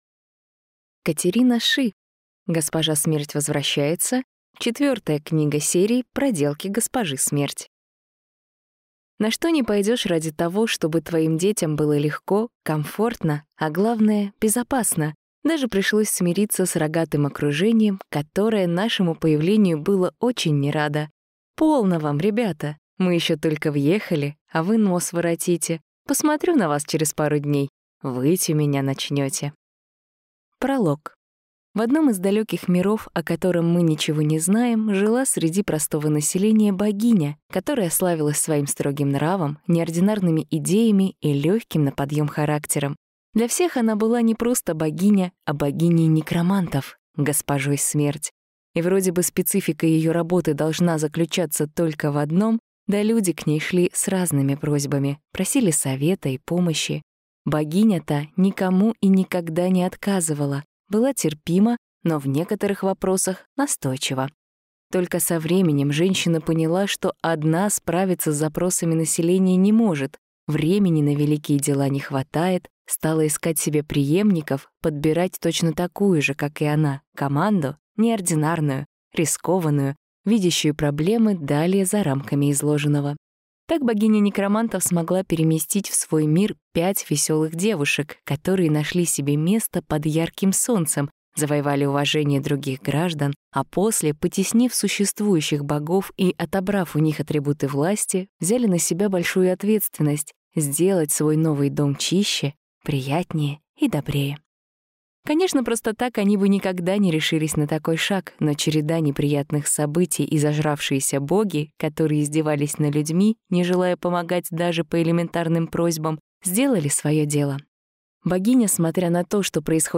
Аудиокнига Госпожа Смерть возвращается | Библиотека аудиокниг
Прослушать и бесплатно скачать фрагмент аудиокниги